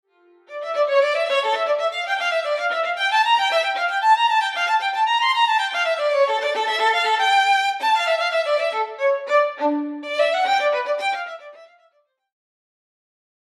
fast played staccatos alternately with fast played legatos (with shortened last staccatos + CC64)